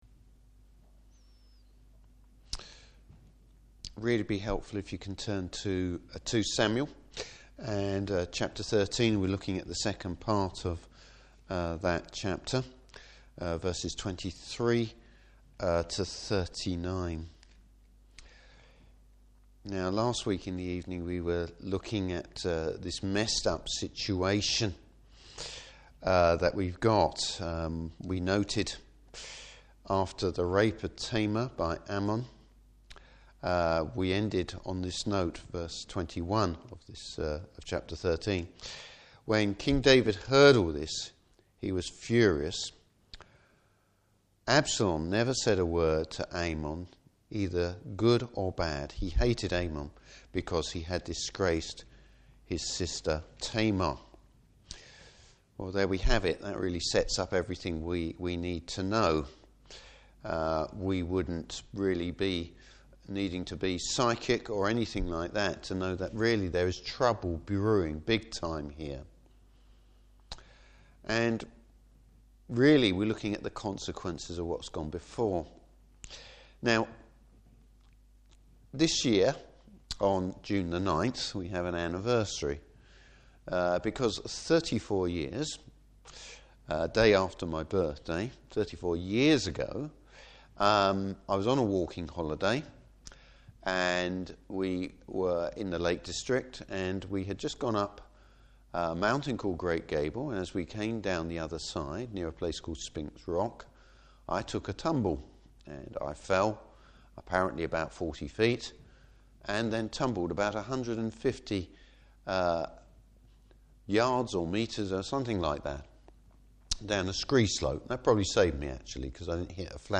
Service Type: Evening Service Sin begets sin!